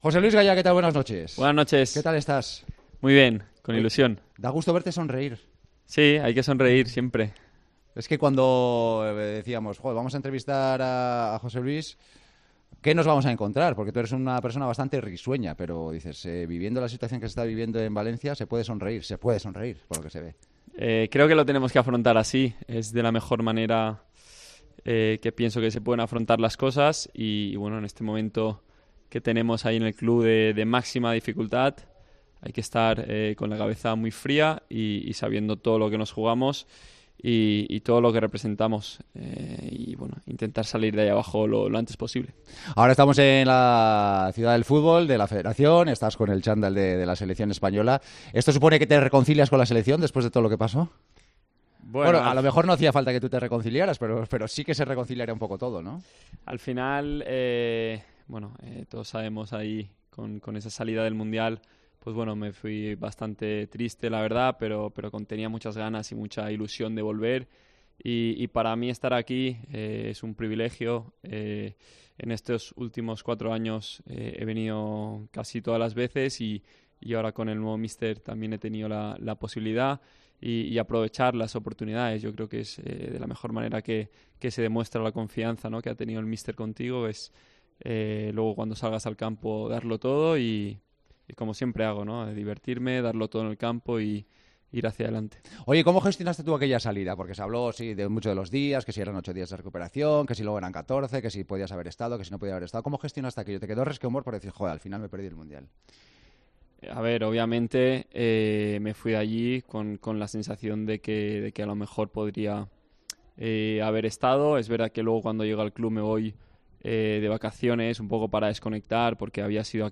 José Luis Gayà ha sido protagonista esta noche en El Partidazo de COPE con Joseba Larrañaga desde la concentración de España en Las Rozas.